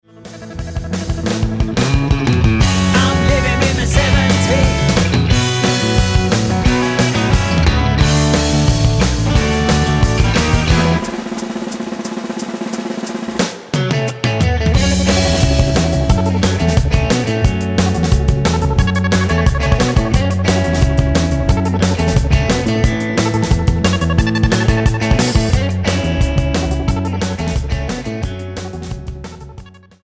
Tonart:F#m mit Chor
Die besten Playbacks Instrumentals und Karaoke Versionen .